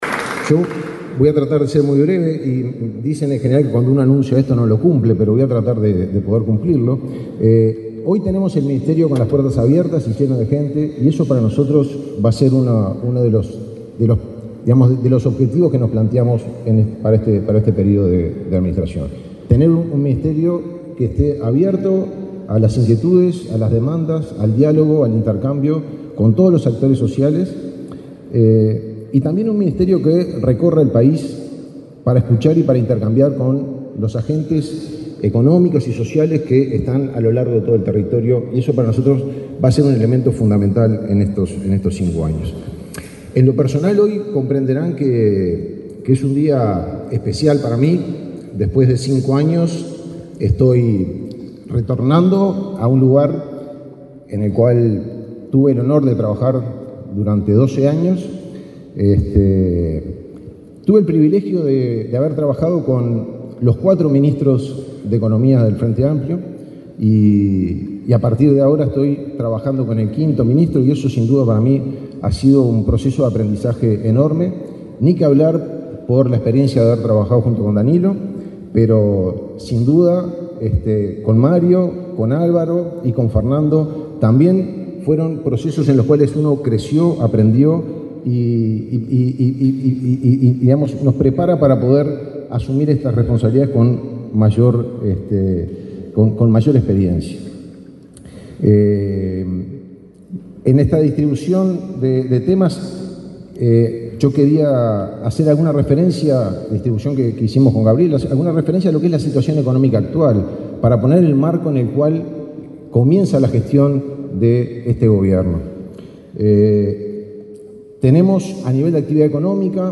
Palabras del subsecretario de Economía y Finanzas, Martín Vallcorba
Palabras del subsecretario de Economía y Finanzas, Martín Vallcorba 05/03/2025 Compartir Facebook X Copiar enlace WhatsApp LinkedIn El presidente de la República, profesor Yamandú Orsi, y la vicepresidenta, Carolina Cosse, asistieron al acto de asunción de las autoridades del Ministerio de Economía y Finanzas, Gabriel Oddone, como ministro, y Martín Vallcorba, como subsecretario.